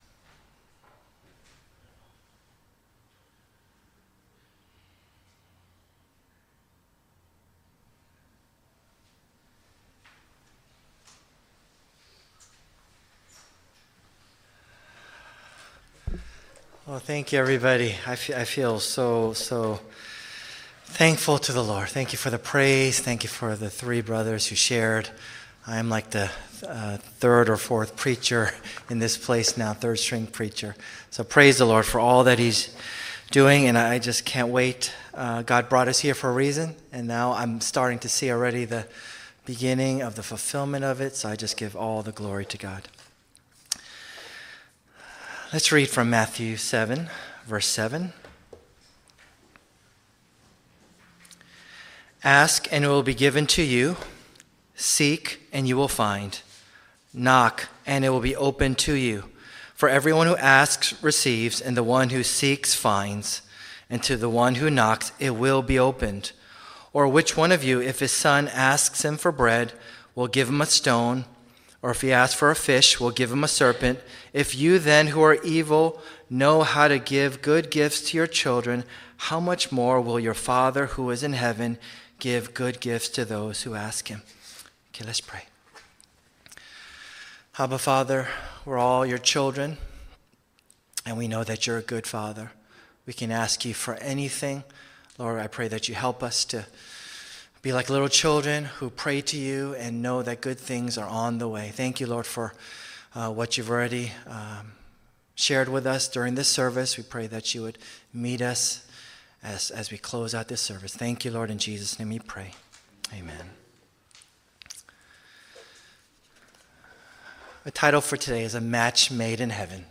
The sermon discusses the necessity of asking God for both small and big things, as He is a loving Father who cares for His children.